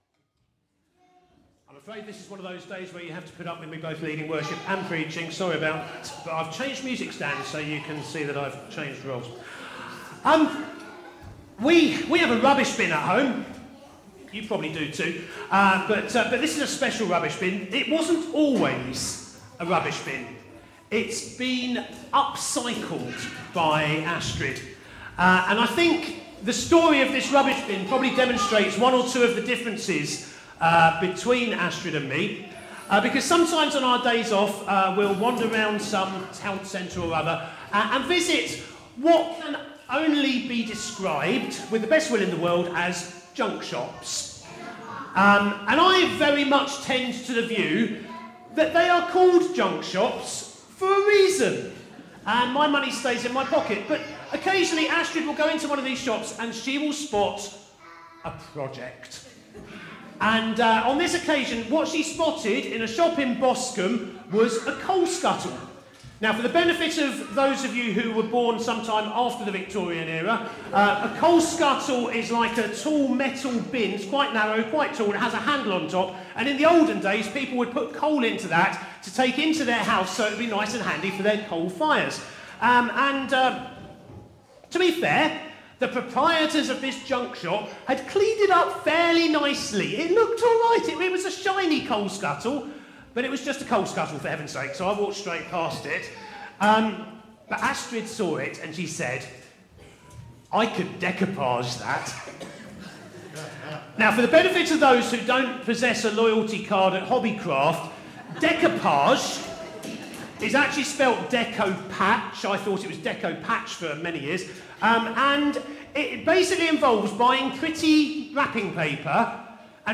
A message from the series "Stand Alone Sermons 2025."